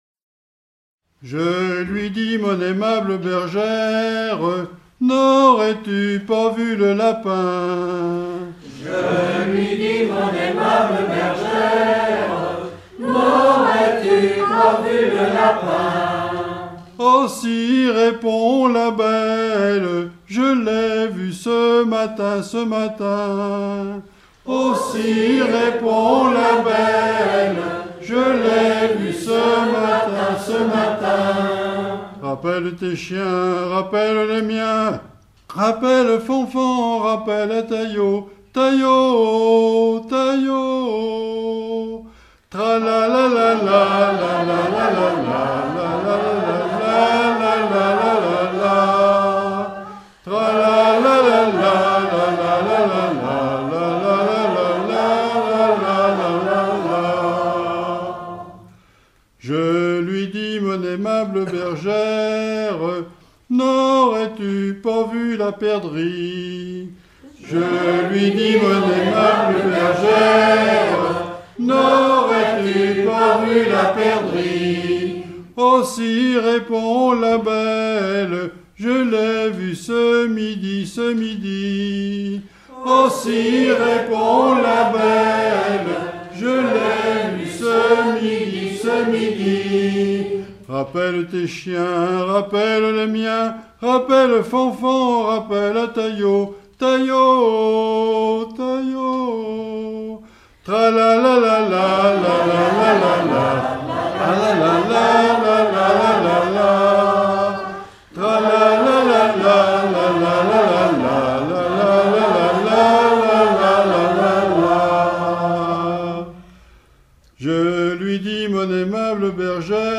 Usage d'après l'informateur circonstance : vénerie
Genre énumérative